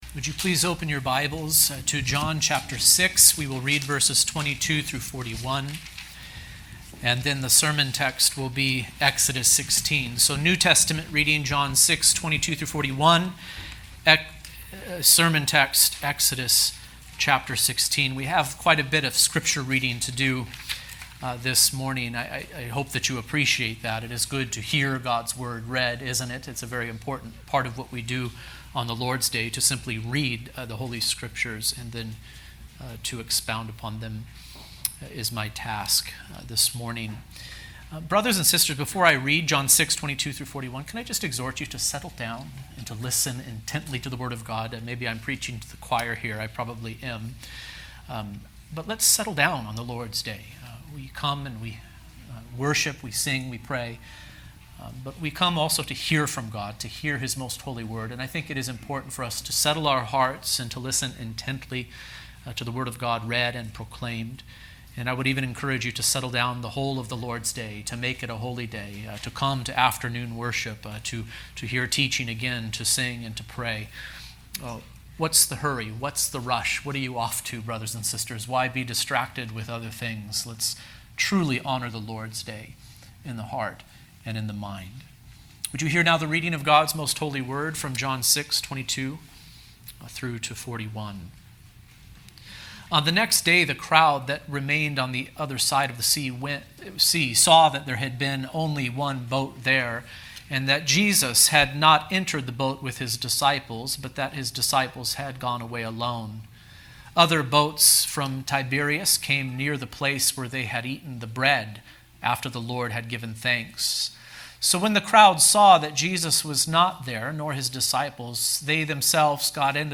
Manna From Heaven | SermonAudio Broadcaster is Live View the Live Stream Share this sermon Disabled by adblocker Copy URL Copied!